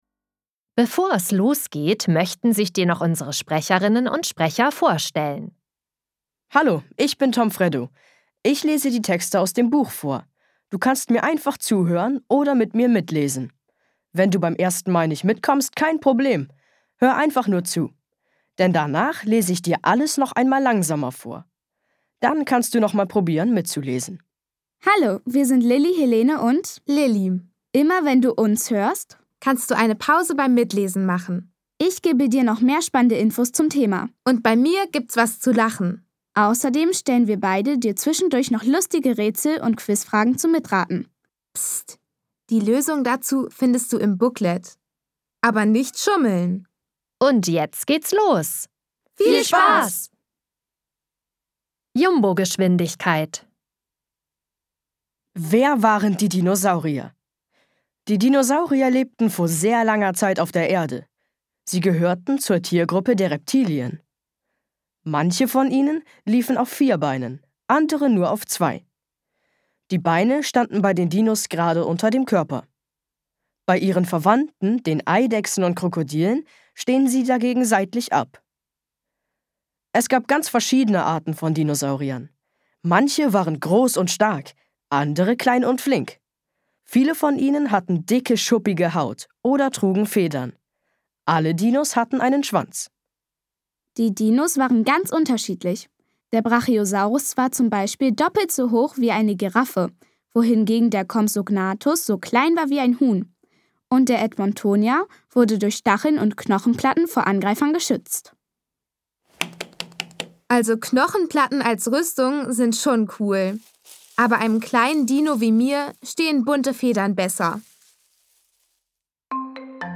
Dank vielseitiger Rätsel-Pausen mit zum Beispiel Quizfragen und Silbenrätseln werden Kinder dabei ganz spielerisch ans Lesen und Schreiben herangeführt.Liegt zudem das Buch vor, können die Erstlesetexte mithilfe der unterschiedlichen Vorlesegeschwindigkeiten kinderleicht mitgelesen werden.-Von lesestarken Kindern gesprochen: Vorbilder als Motivation zum Selbstlesen-Mit Rätseln und Quizfragen das Hör- und Textverständnis fördern-Wissen in unterschiedlichen Vorlesegeschwindigkeiten zum Zuhören und - wenn das Buch vorhanden ist - auch zumMitlesen-Mit Klangsignal zum Umblättern, falls parallel das Buch gelesen wird-Mit Tipps für Eltern im Booklet